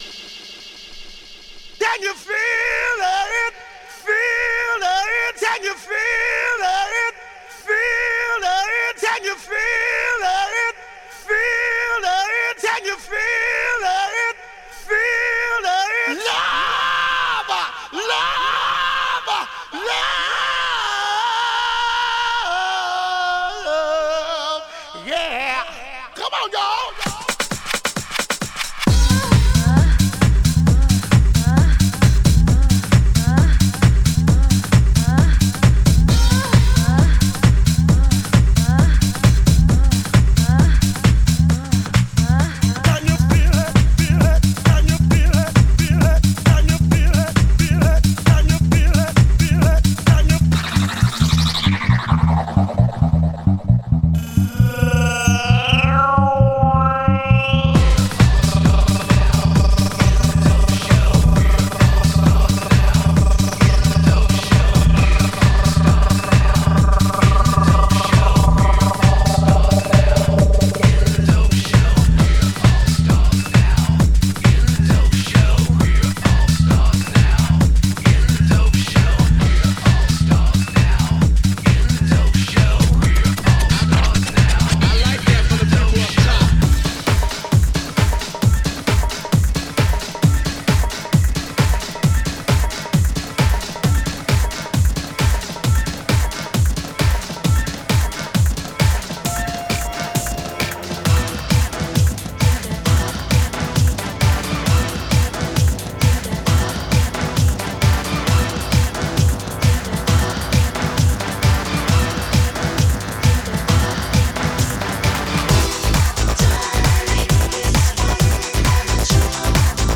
MEGAMIX